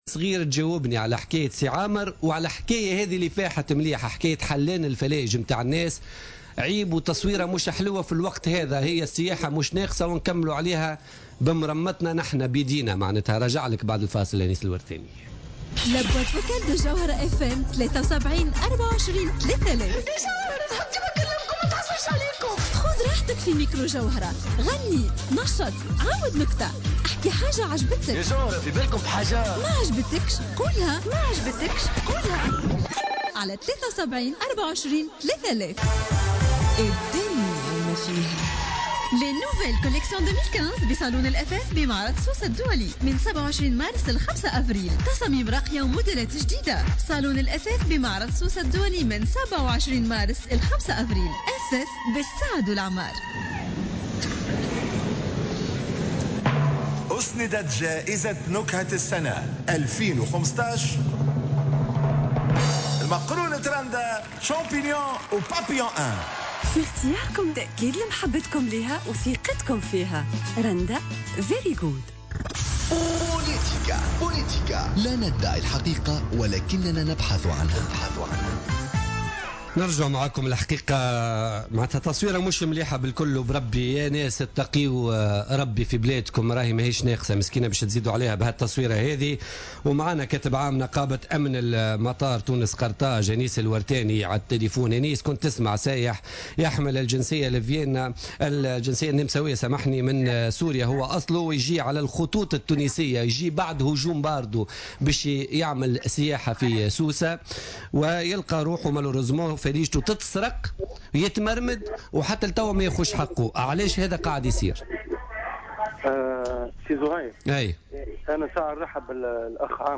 sur les ondes de Jawhara FM dans le cadre de l’émission Politica